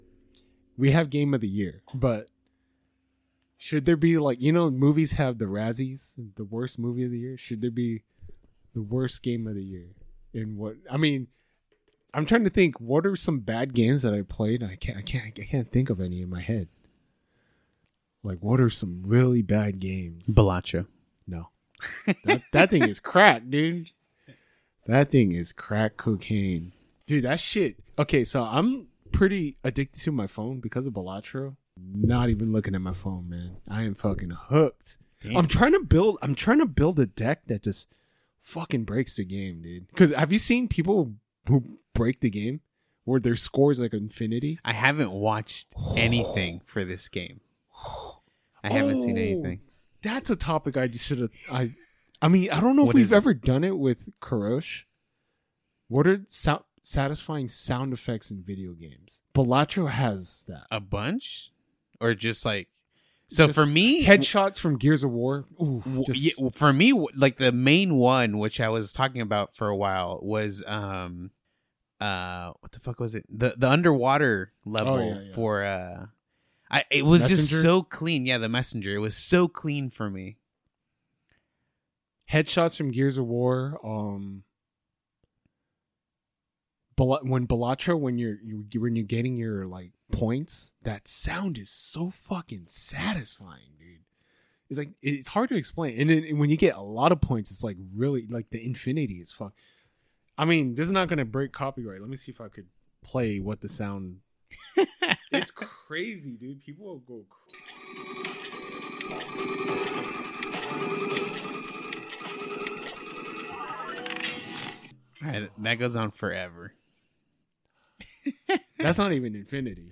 WARNING - This episode has some weird audio artifacting/glitching.